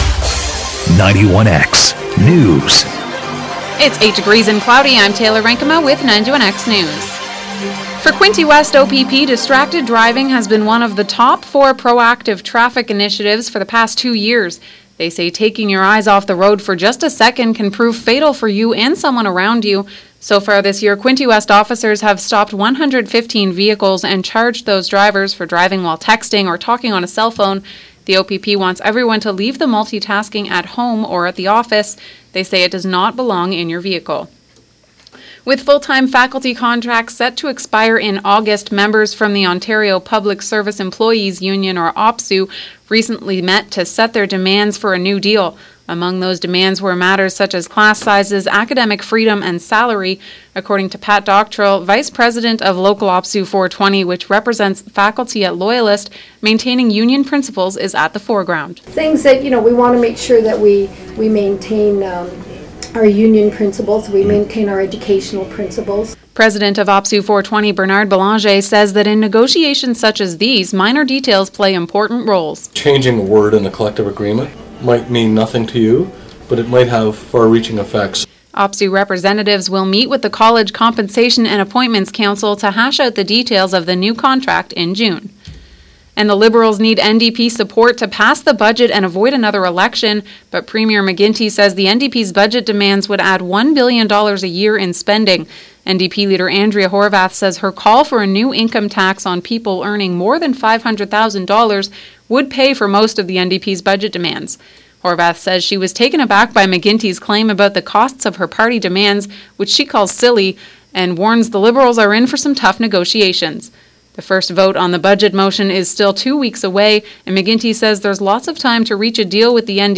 91X News Quinte West OPP are cracking down on distracted driving.